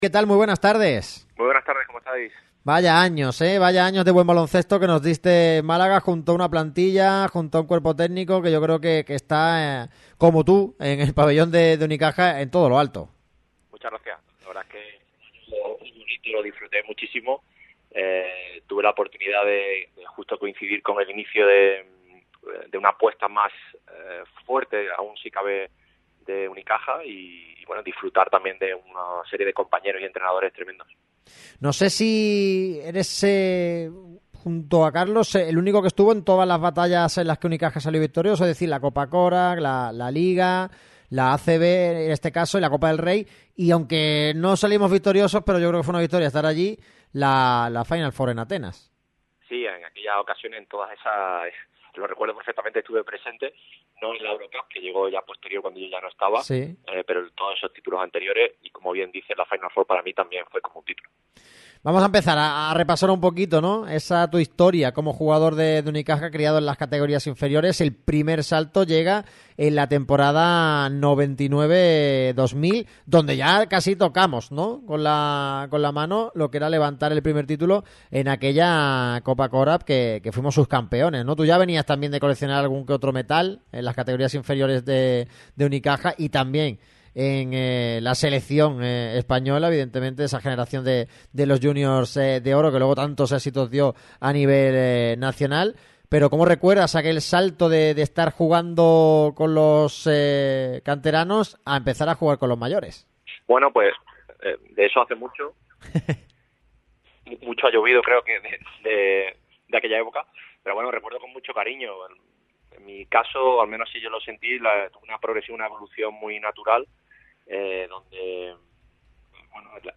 Nueva entrega de 'Marca Basket, 40 años en verde y morado', patrocinado por la Fundación Unicaja. Este jueves charlamos con Berni Rodríguez.